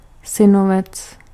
Ääntäminen
Ääntäminen France: IPA: [ɛ̃ nə.vø] Tuntematon aksentti: IPA: /nə.vø/ Haettu sana löytyi näillä lähdekielillä: ranska Käännös Ääninäyte Substantiivit 1. synovec {m} Suku: m .